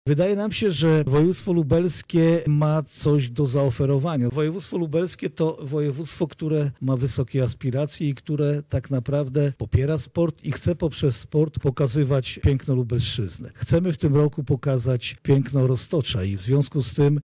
– mówi marszałek województwa lubelskiego Jarosław Stawiarski.